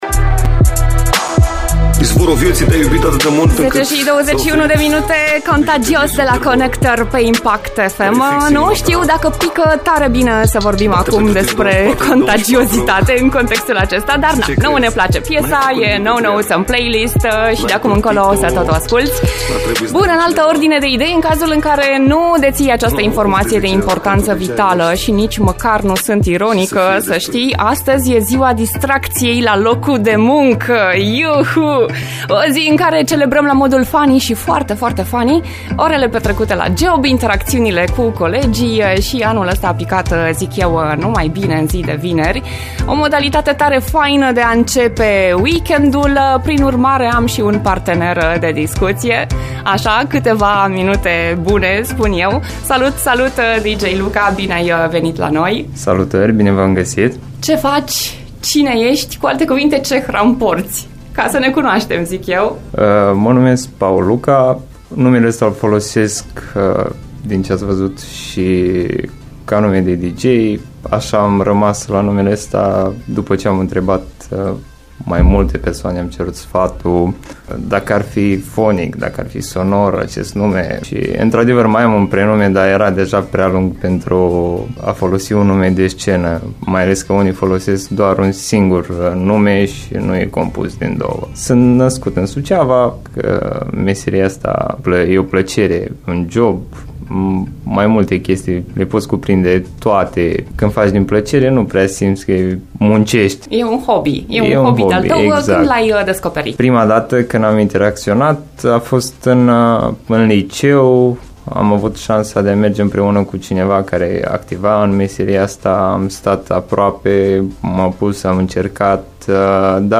O discuție fresh și relaxantă.